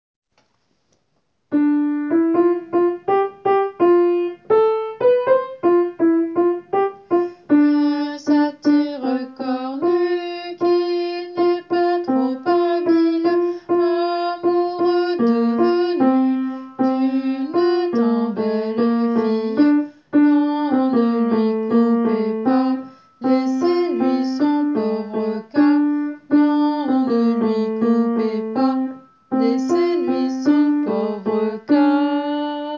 Basse :
satire-basse.wav